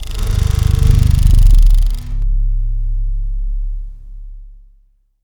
rodretract.wav